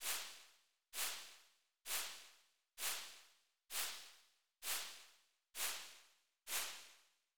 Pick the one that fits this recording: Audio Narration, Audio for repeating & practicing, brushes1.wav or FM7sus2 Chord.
brushes1.wav